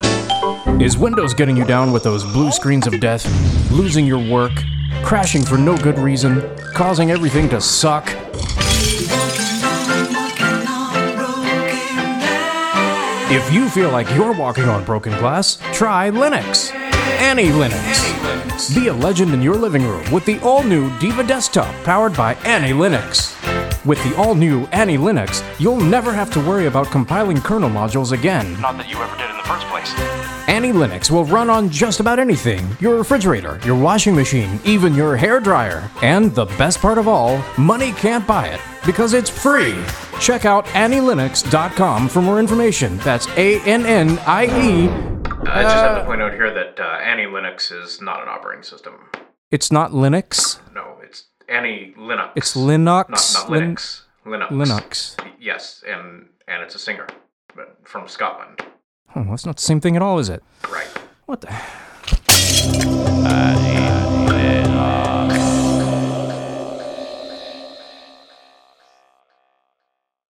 Along with music it contains a jingles folder with silly ads that are played every so often ... one of them is the Annie Linux ad, which has been there for a few years now and is still a hit.